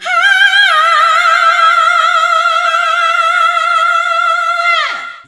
SCREAM 2  -L.wav